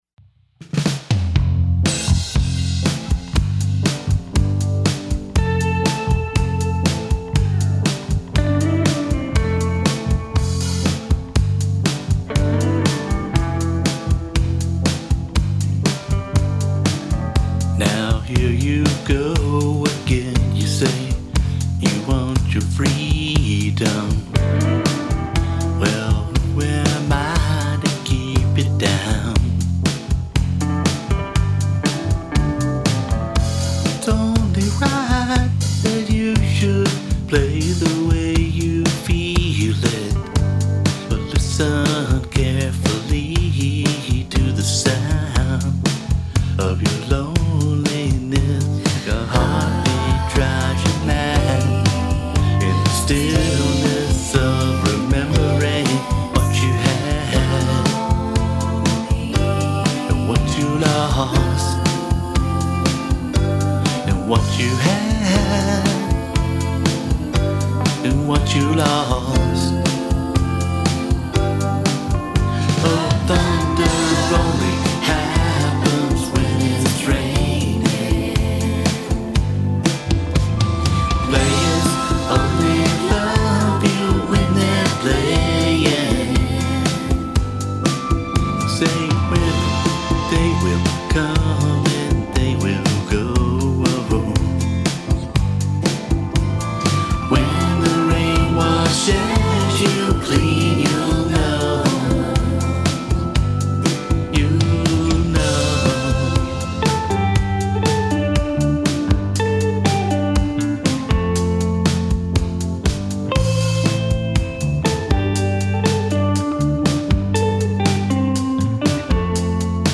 My latest mix from my basement.